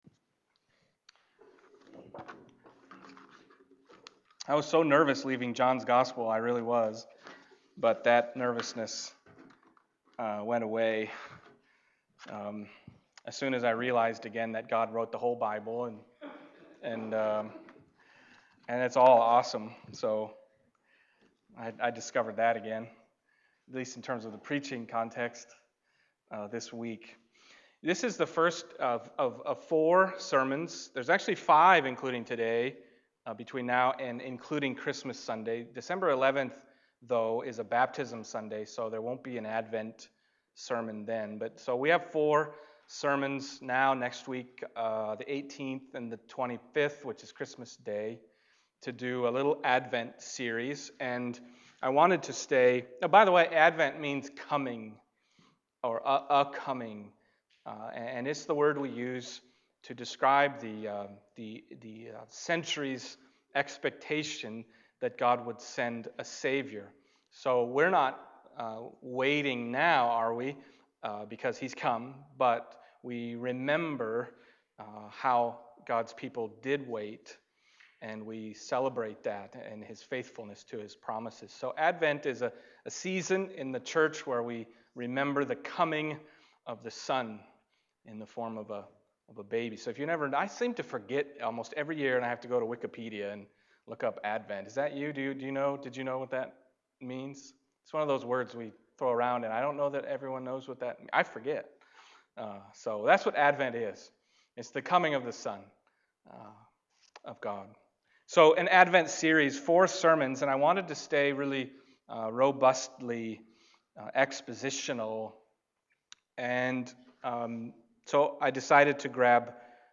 Preacher
Passage: Luke 1:67-79 Service Type: Sunday Morning